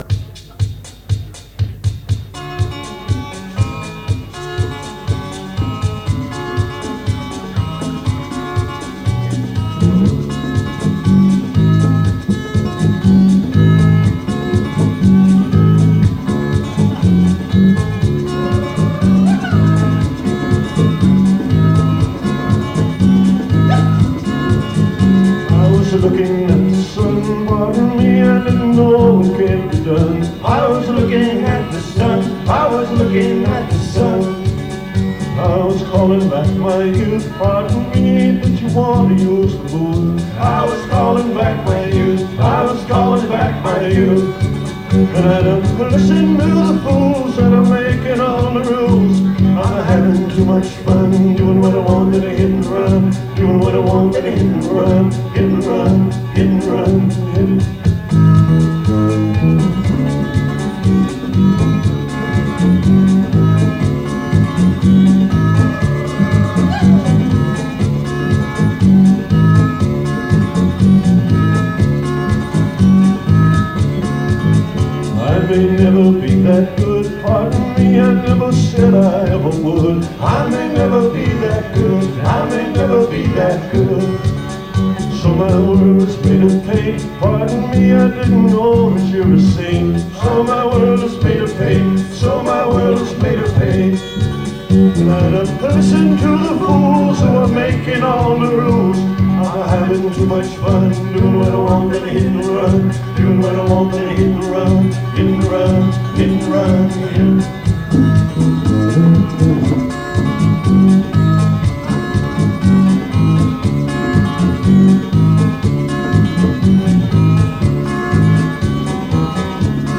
In Concert San Diego 1987